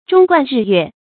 忠貫日月 注音： ㄓㄨㄙ ㄍㄨㄢˋ ㄖㄧˋ ㄩㄝˋ 讀音讀法： 意思解釋： 忠誠之心可以貫通日月。形容忠誠至極。